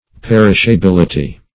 Perishability \Per`ish*a*bil"i*ty\, n.